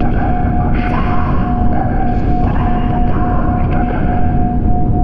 divination-magic-sign-rune-loop.ogg